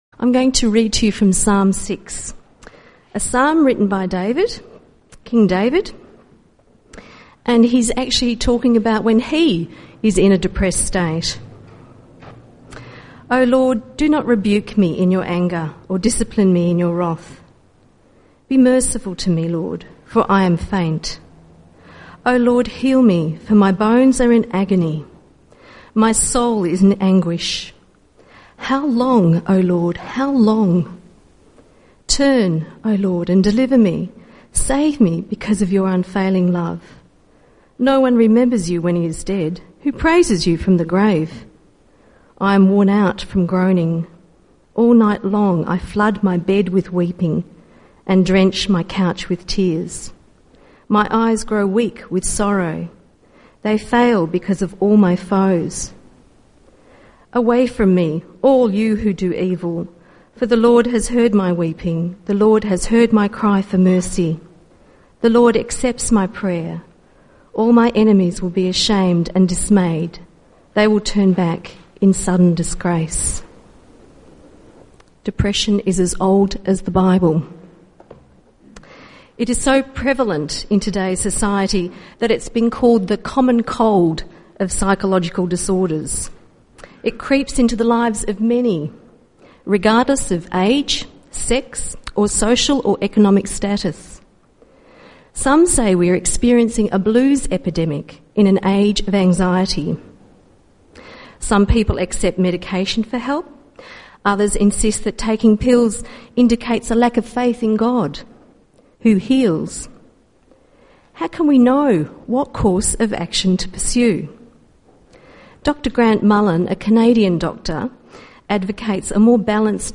Including three guest speakers giving three different perspectives (Doctor, Counsellor and Prayer Ministry).